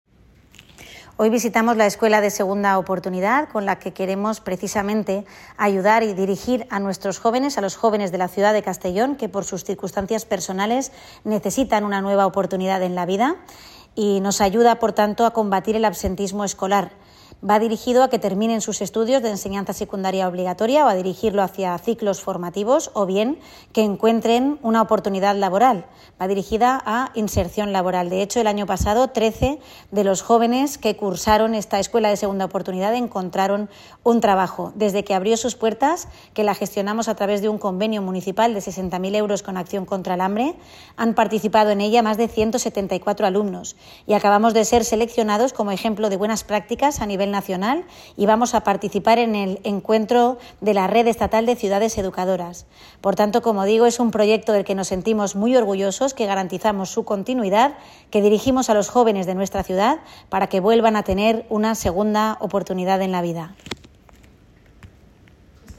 Corte de voz, alcaldesa de Castellón Begoña Carrasco.
CORTE-ALCALDESA-ESCUELA-SEGUNDA-OPORTUNIDAD.mp3